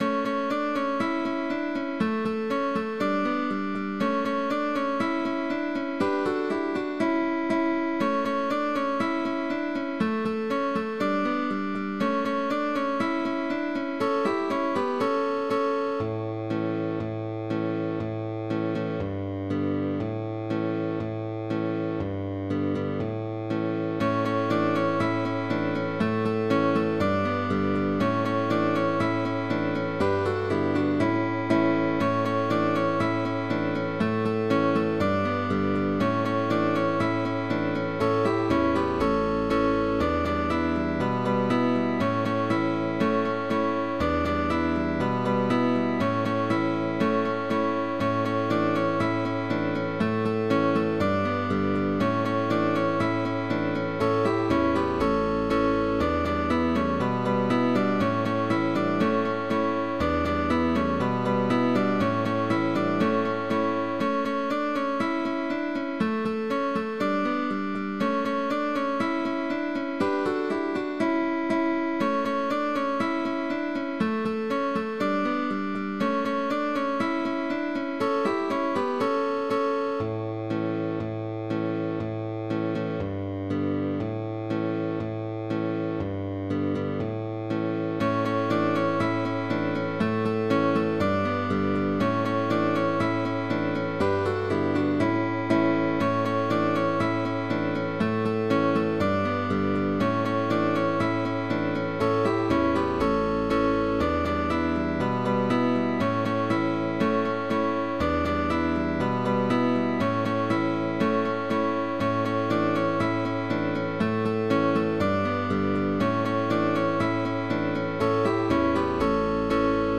CUARTETO de GUITARRAS
Aires celtas adaptado para cuarteto de guitarras.
Con bajo opcional, válido para orquesta de guitarras.
Autor: Popular Irlanda